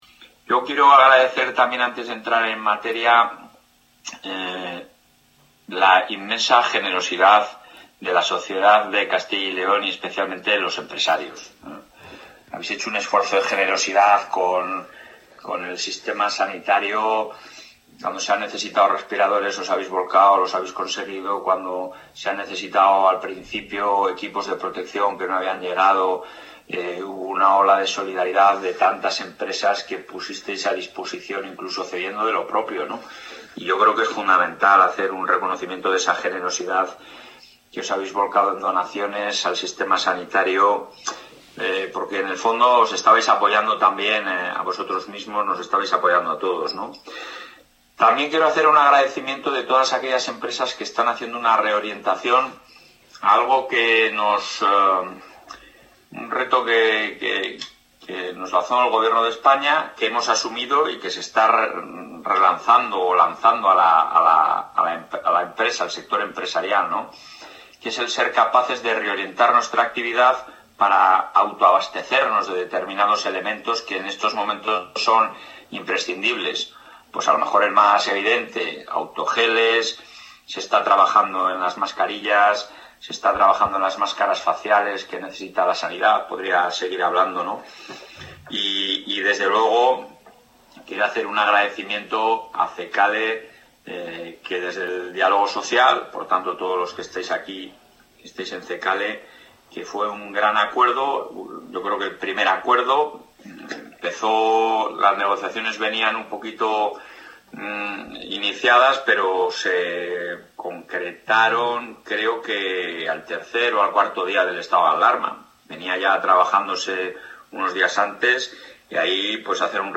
Durante una reunión telemática con la Junta Directiva de CECALE, el presidente de la Junta de Castilla y León, Alfonso...
Intervención del presidente.